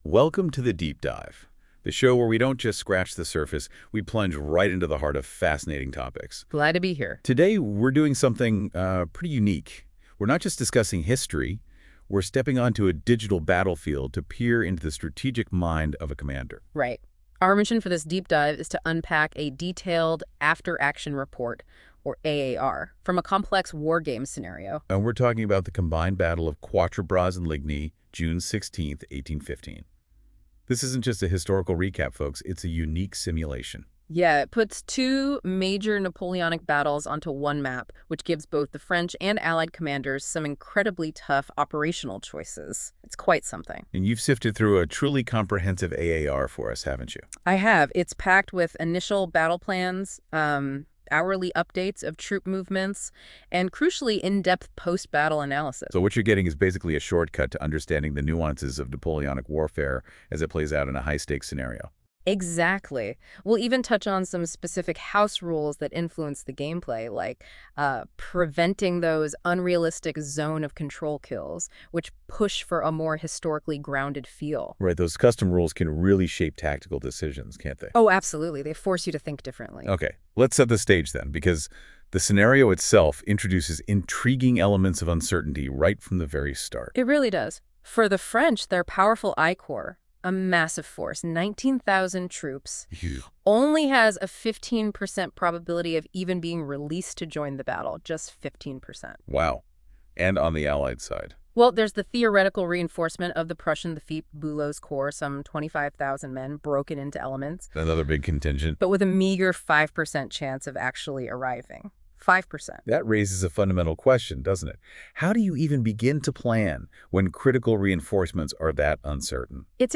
If you'd rather hear it as a spoken piece, there's a podcast-style audio version available here 1.